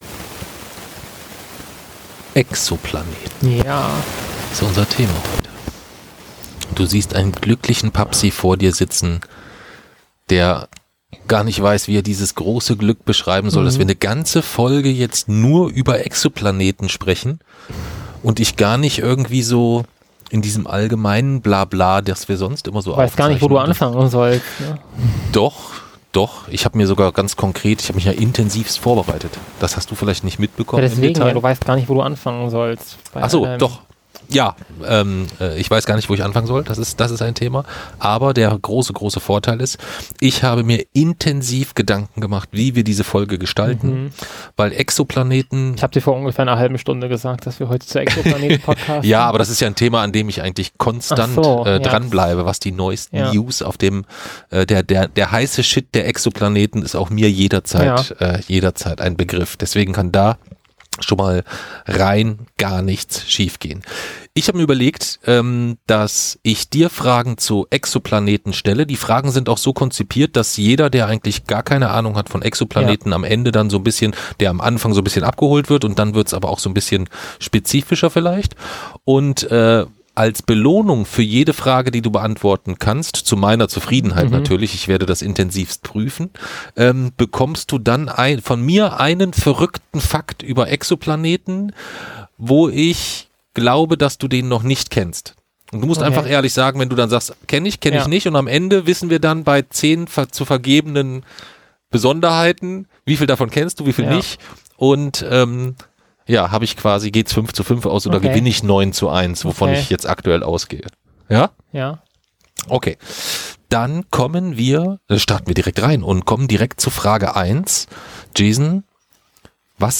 1 #246: Weihnachtliche Jason Dark Lesung (Teil 3) & Jahresrückblick 2025 2:33:12